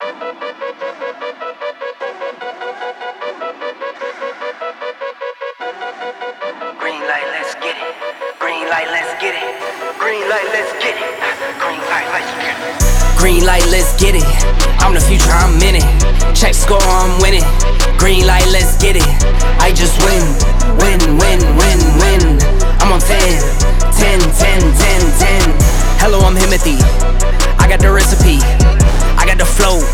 Hip-Hop Hip-Hop Rap
Жанр: Хип-Хоп / Рэп